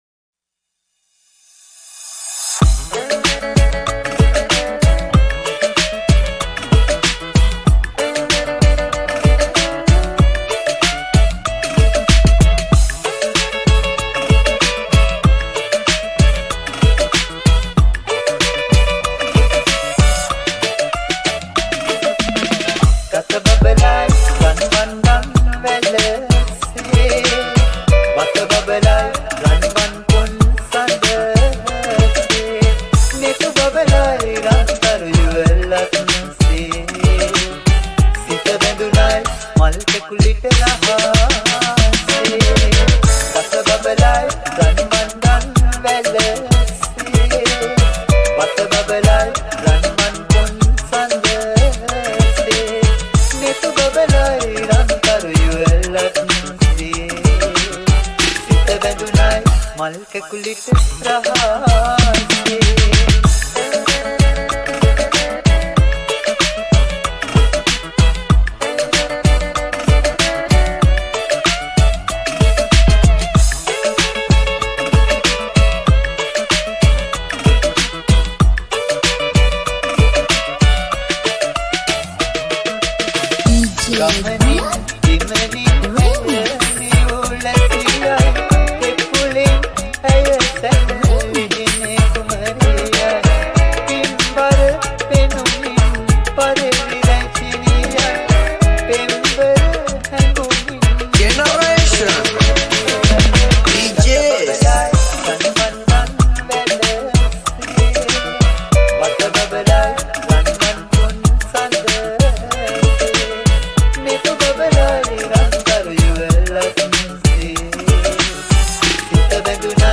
High quality Sri Lankan remix MP3 (3.4).
Rap